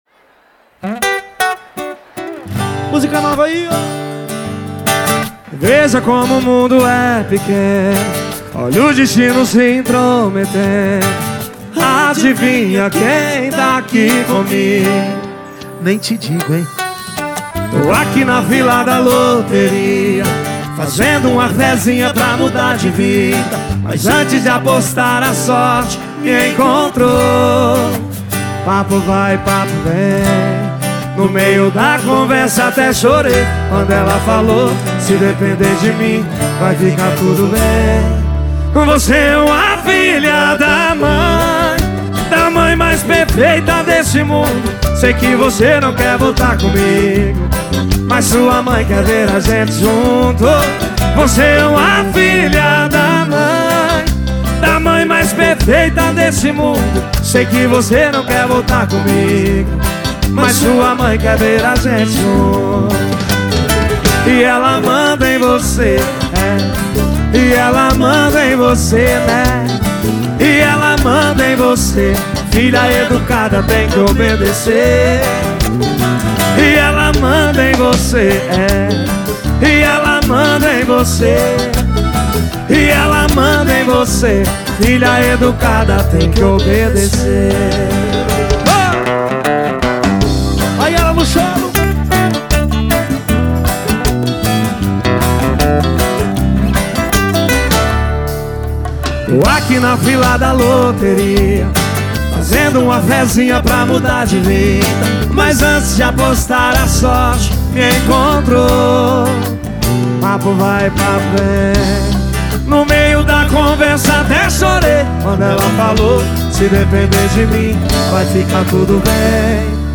Home > Music > Pop > Bright > Smooth > Medium